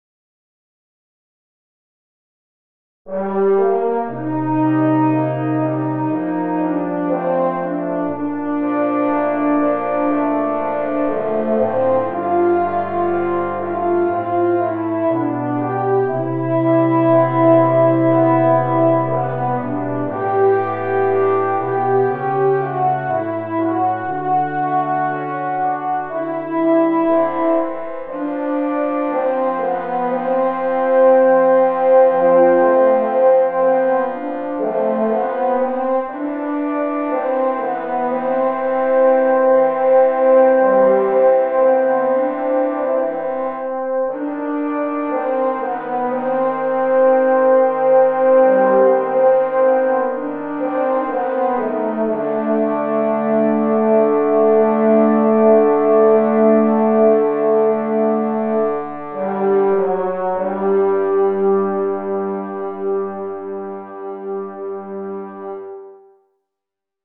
Brass Band version
is a nice melodic ballad in a thinner orchestration.